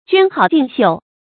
娟好静秀 juān hǎo jìng xiù
娟好静秀发音